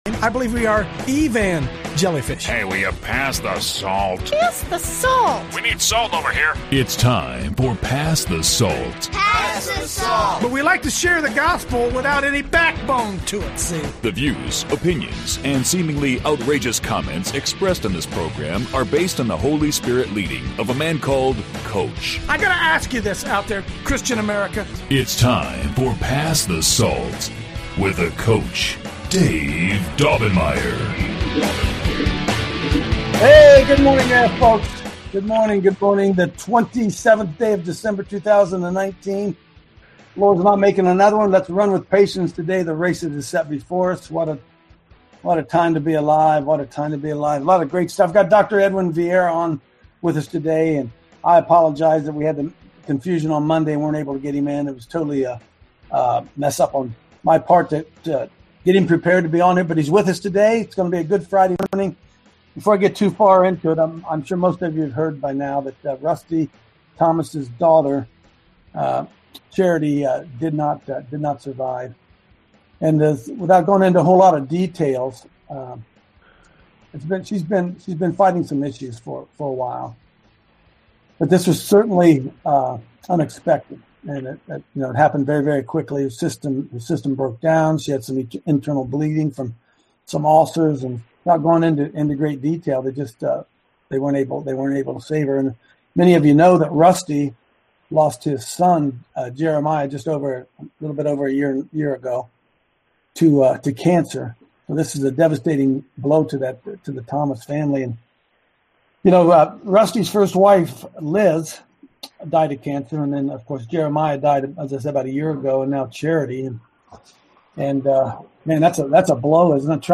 Discussion ensued.